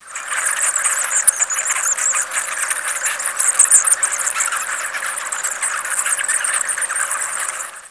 Mastiff bat Site: University of Aberdeen Zoology Museum Format: WAV - 0.175MB Description: Mastiff bat sound If necessary, please download latest versions of QuickTime , RealOne Player , or Windows Media .
freetailed.wav